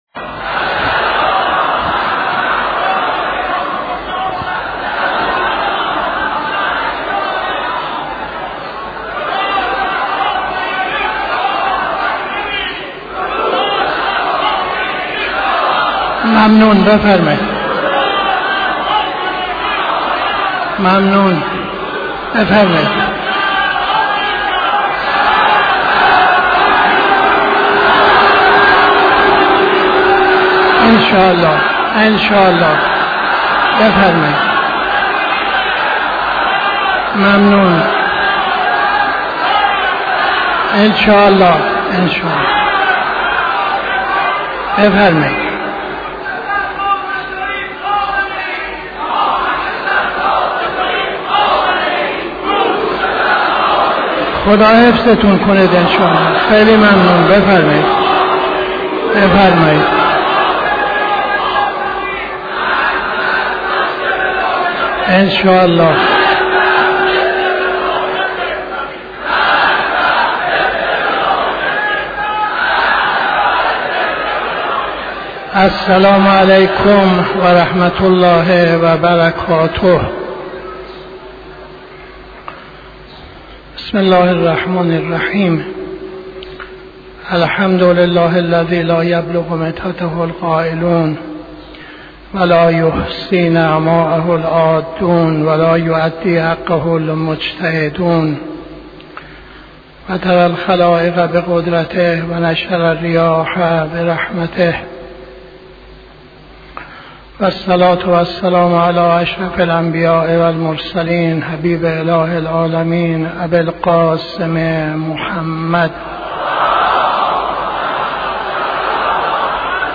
خطبه اول نماز جمعه 09-03-82